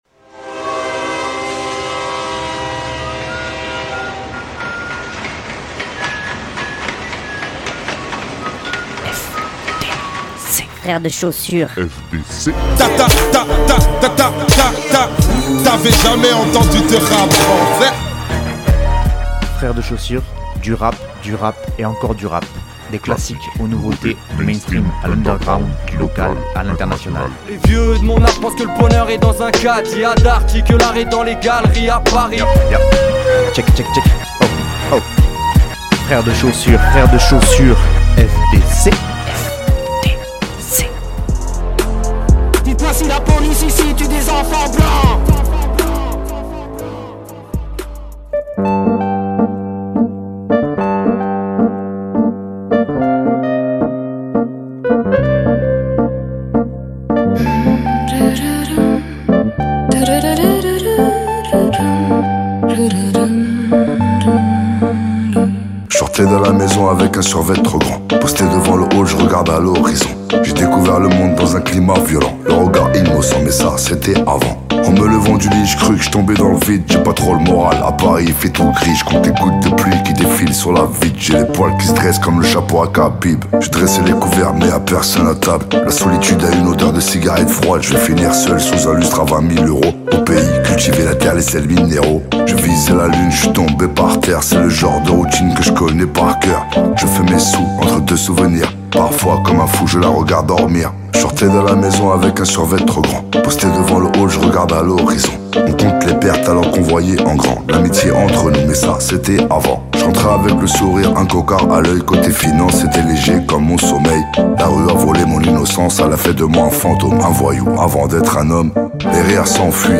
Du rap, du rap et encore du rap.